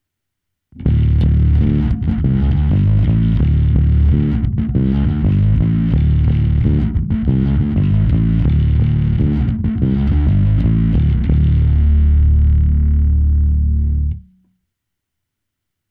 kombinace čistého zvuku a zkreslení se simulací aparátu, nosný základní tón zůstává stále konkrétní,